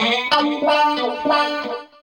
29 GUIT 5 -R.wav